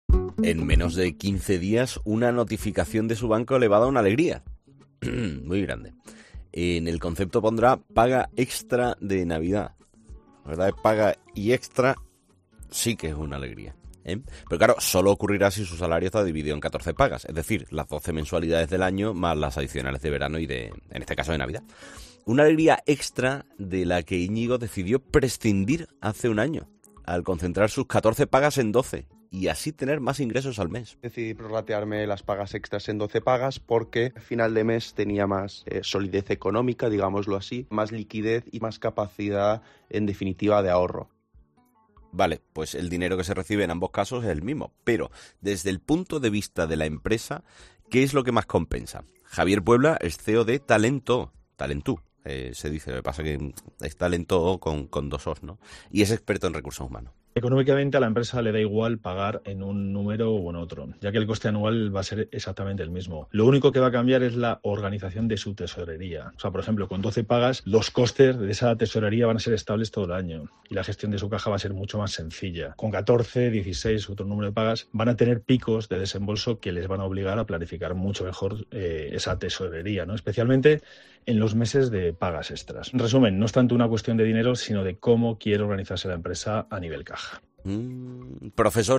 Empleados y empresarios explican si prefieren cobrar o pagar el salario en 12 pagas